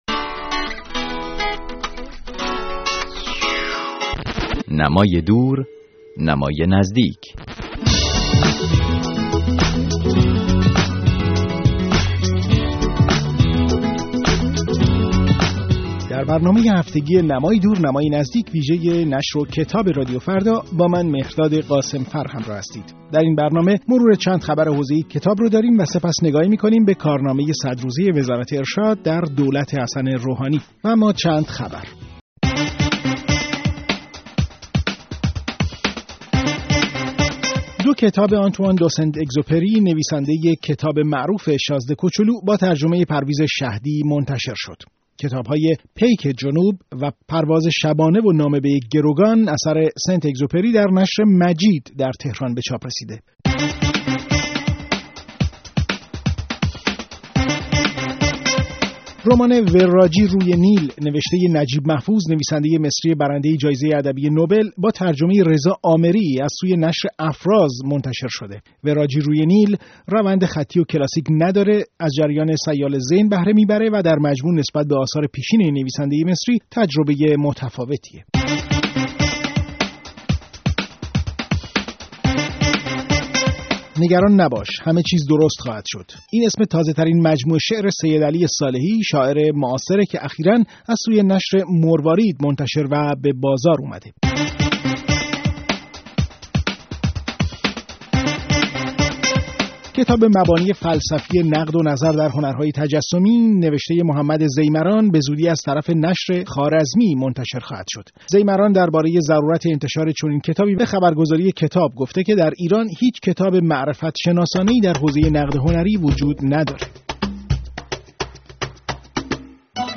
نمای دور نمای نزدیک؛ گفت‌و‌گوی